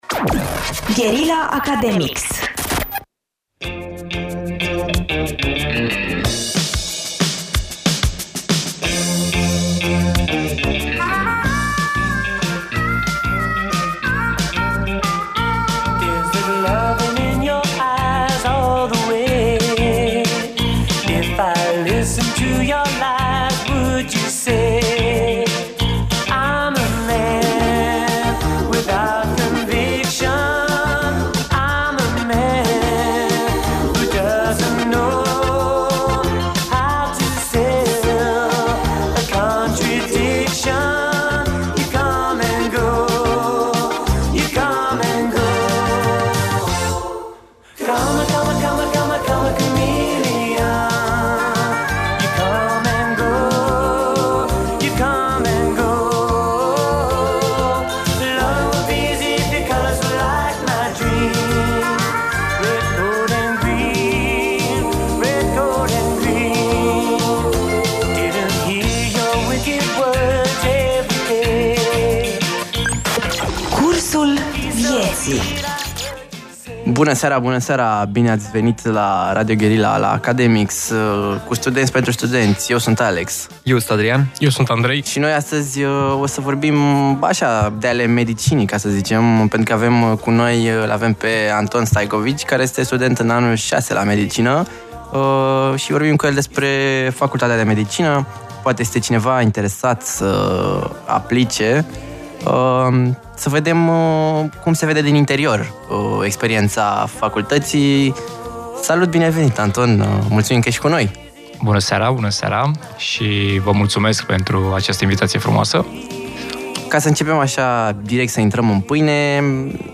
Academics este emisiunea de la Radio Guerrilla ce îi are drept gazde pe elevii din primele sezoane Uman Real, care acum au crescut și au devenit studenți.